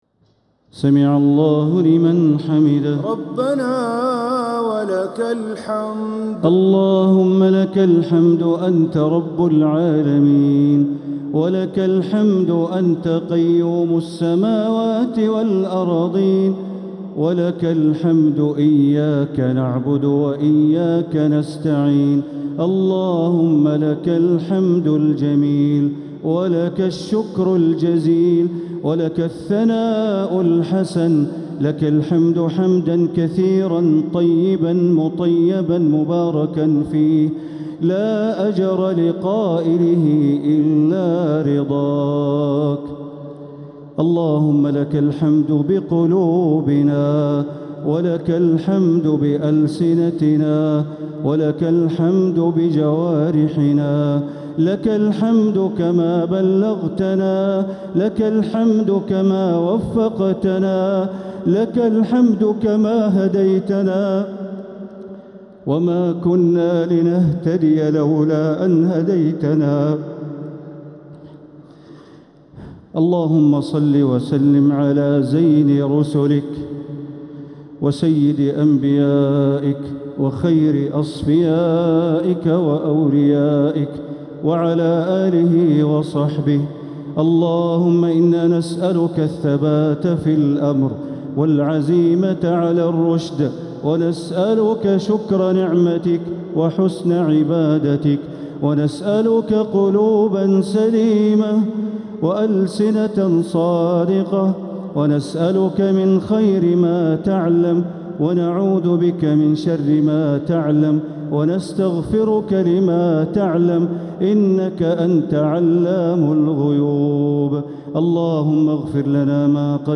دعاء القنوت ليلة 14 رمضان 1447هـ | Dua 14th night Ramadan 1447H > تراويح الحرم المكي عام 1447 🕋 > التراويح - تلاوات الحرمين